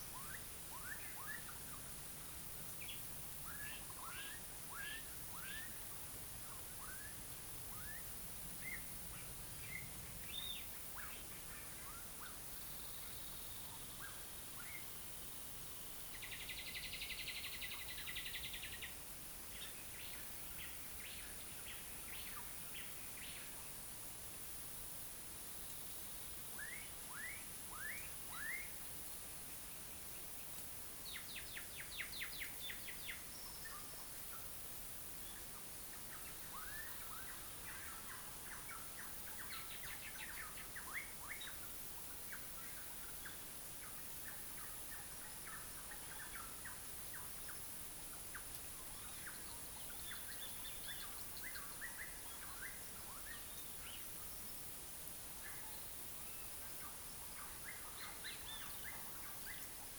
Paisajes sonoros
(Bosques)
Paisaje-sonoros-bosque.mp3